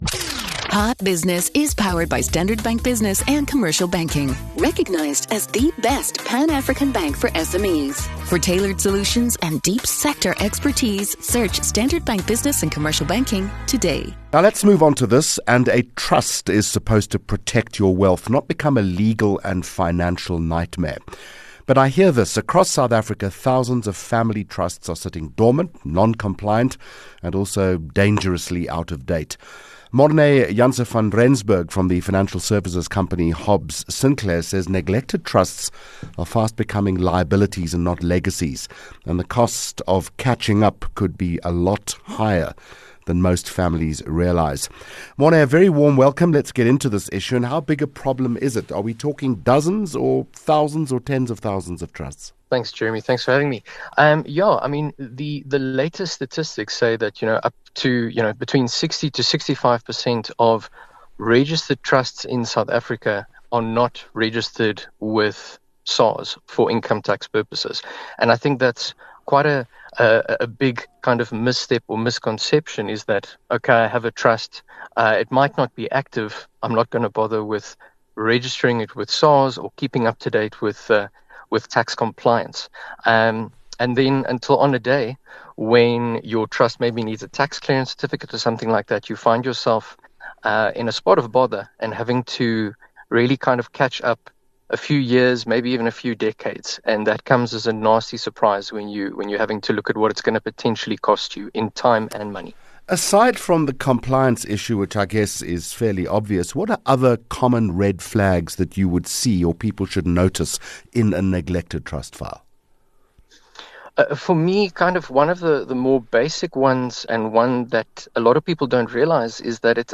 Hot Business Interview